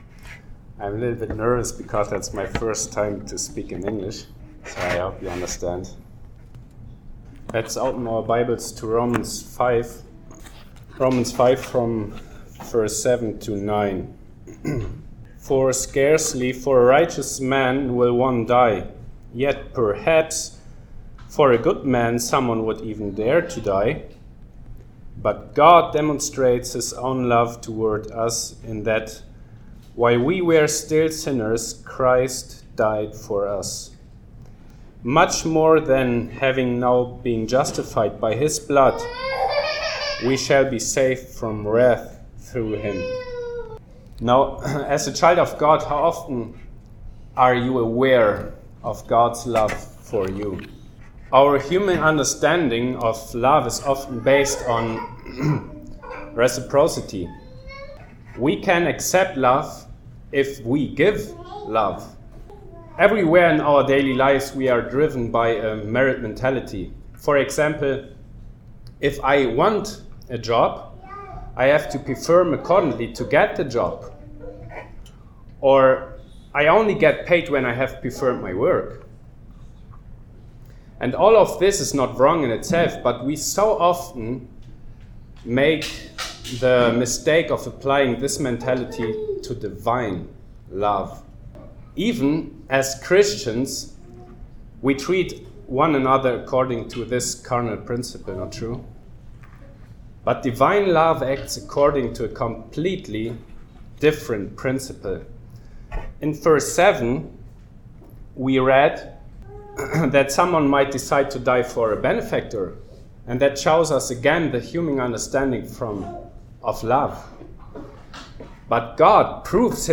Passage: Romans 5: 7-9 Service Type: Sunday Morning